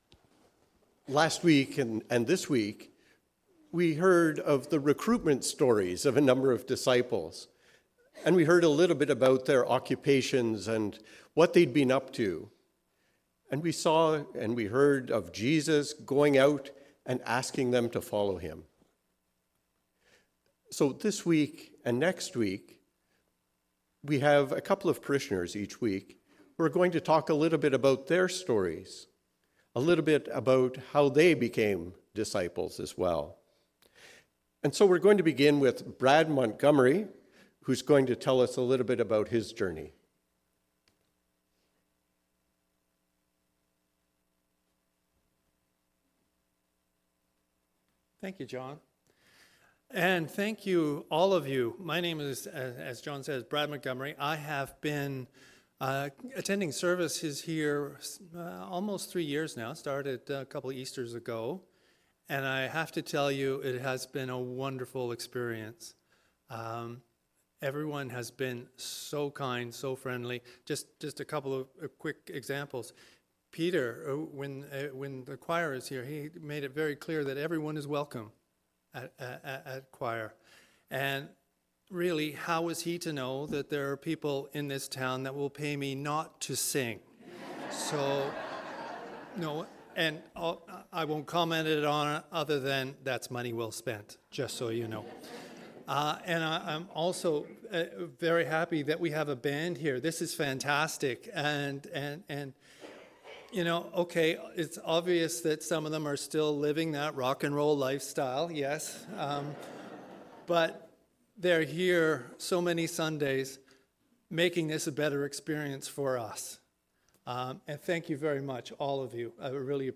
Sermon-21-January-2024.mp3